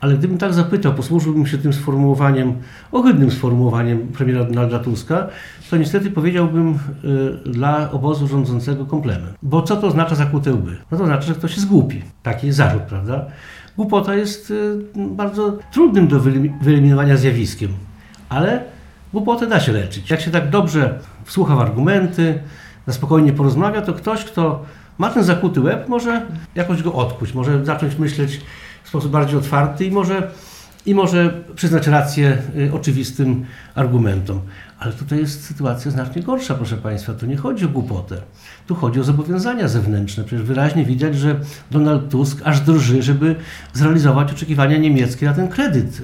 – Dlaczego rząd nie chce procedować propozycji Karola Nawrockiego, prezydenta RP w sprawie SAFE ZERO ?- pytał w poniedziałek (16.03) na konferencji prasowej w Suwałkach Jarosław Zieliński, poseł Prawa i Sprawiedliwości.